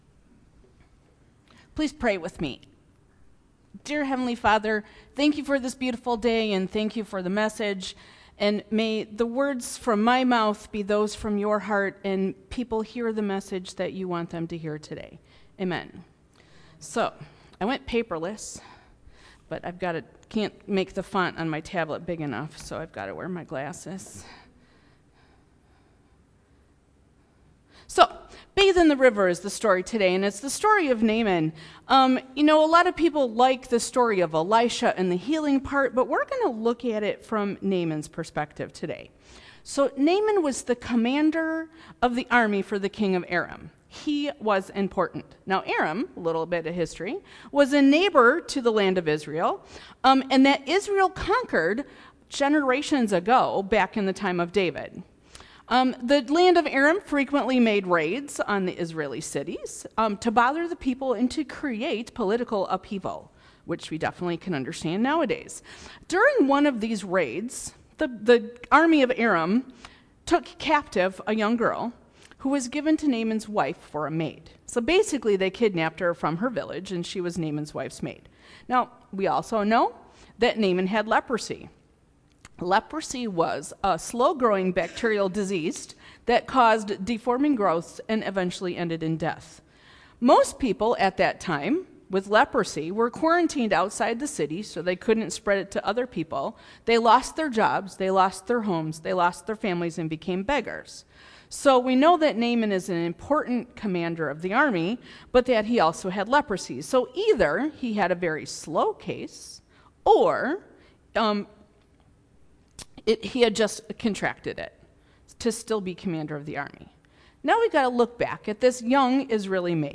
Sermon - July 07 - Hartland United Methodist Church